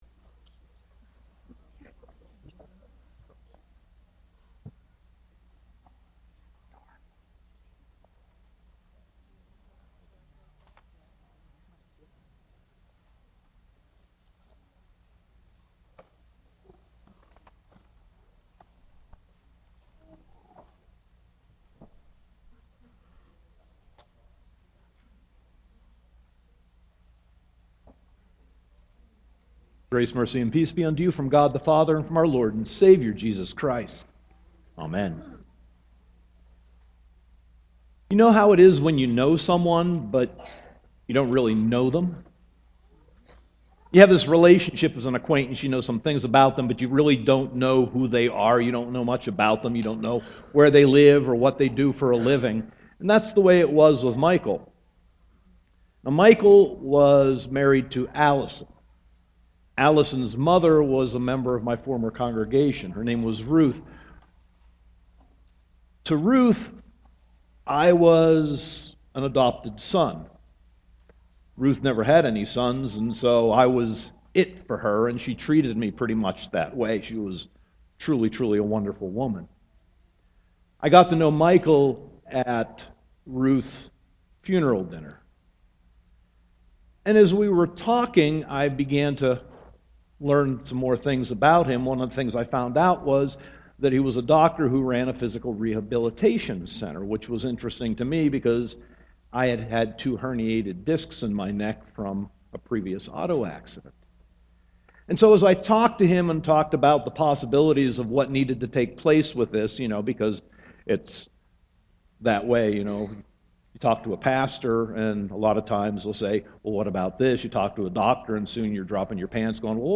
Sermon 4.20.2014 Easter Sunday -